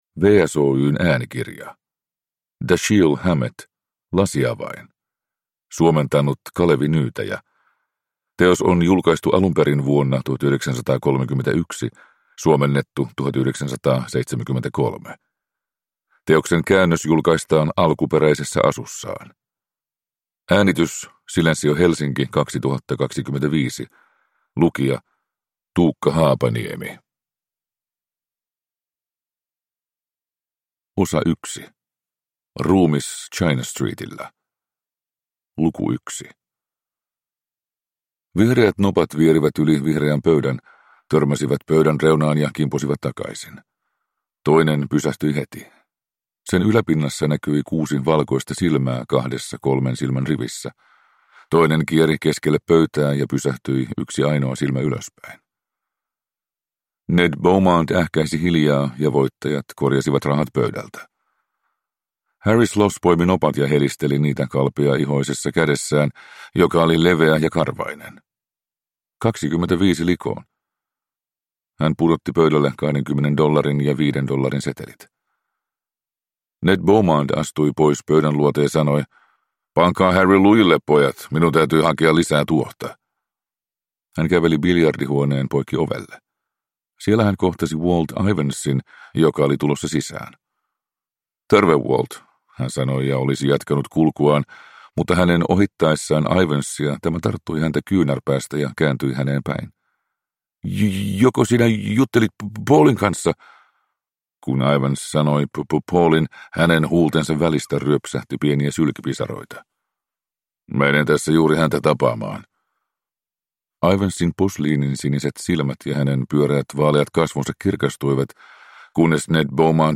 Lasiavain – Ljudbok